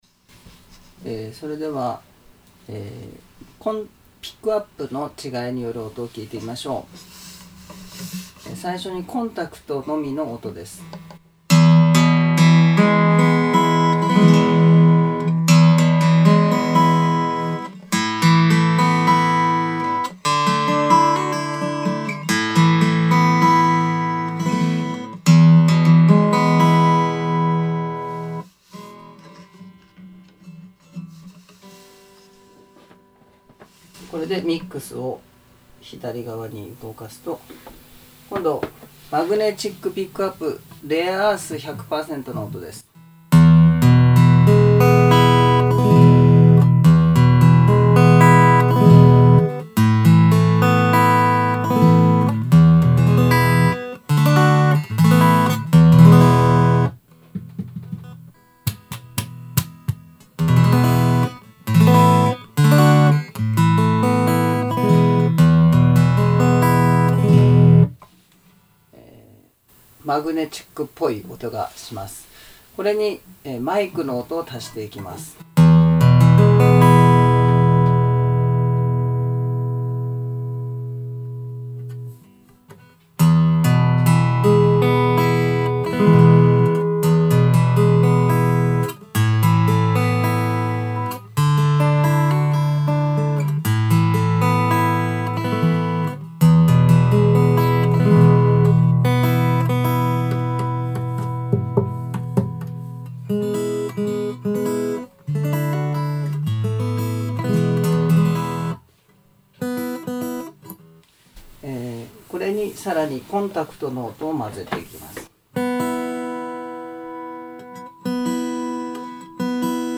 セミナー風 マグのみ ピエゾのみ マイクのみ とそれぞれを混ぜた音を聞く
ニコアースブレンドの音をそれぞれ，マグのみ，コンタクトのみ，それにマイクを混ぜた音を聞いていただきます。